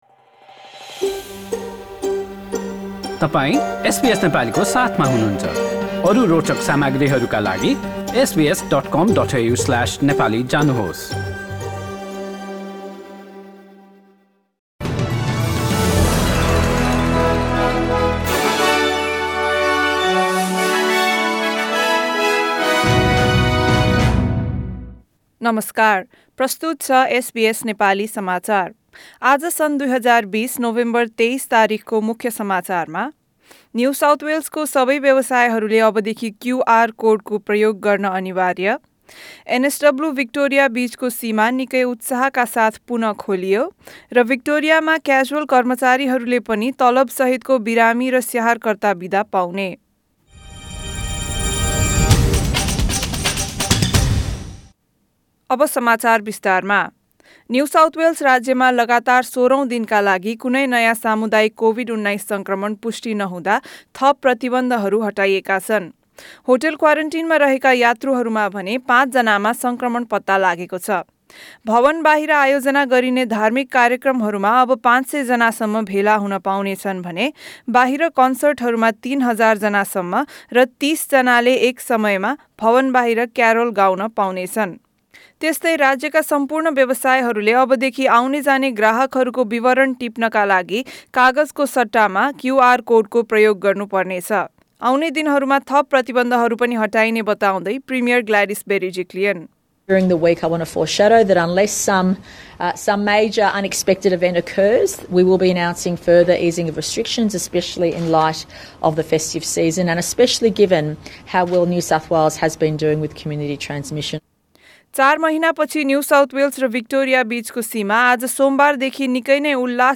एसबीएस नेपाली अस्ट्रेलिया समाचार: सोमबार २३ नोभेम्बर २०२०